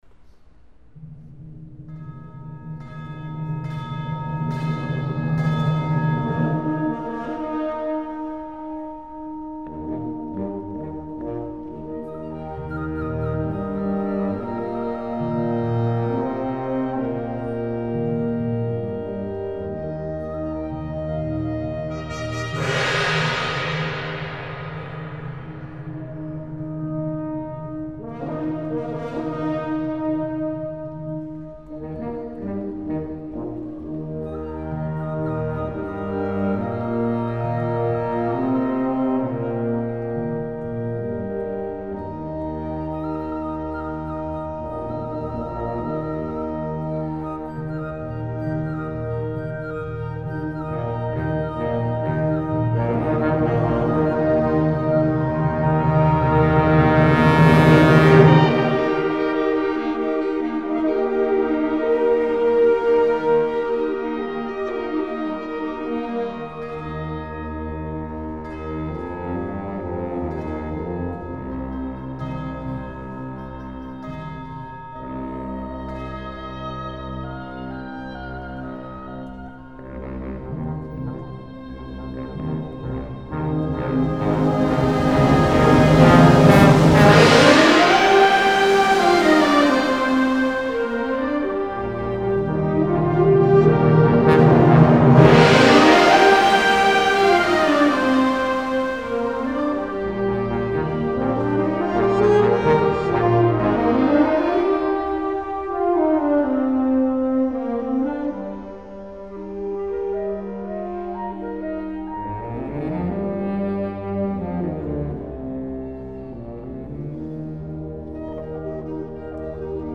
Música costarricense